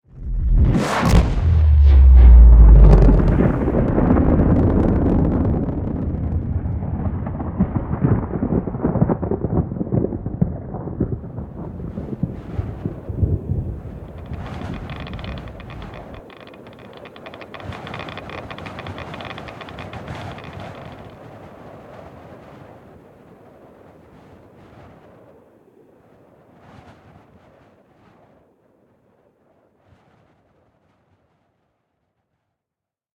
Ship_explode_(2).ogg